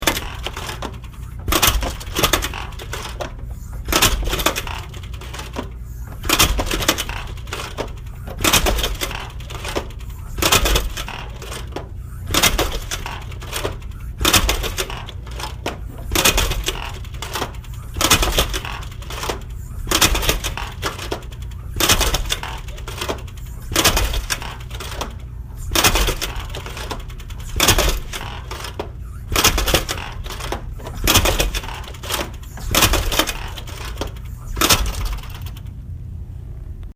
Field Recording #6
An emergency exit door opening and closing.
Emergency-Door.mp3